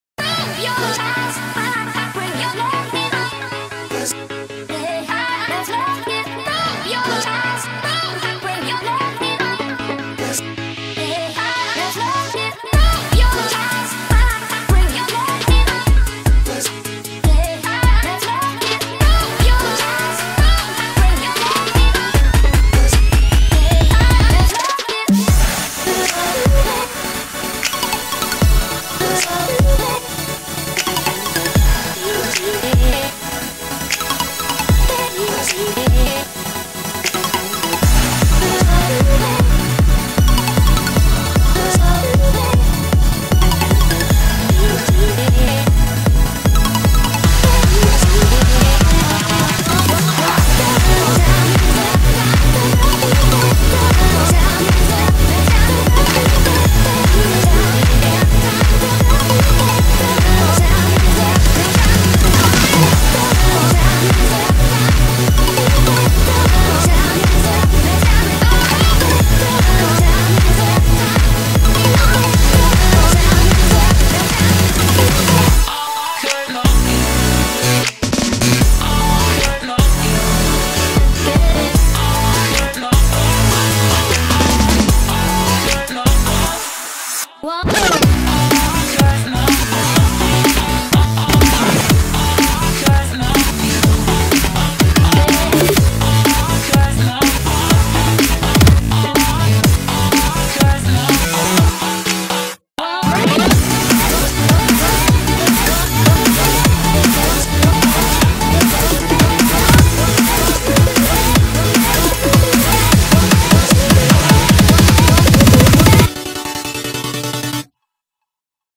BPM124-153
Audio QualityPerfect (High Quality)
Comentarios[ELECTRO NU DISCO]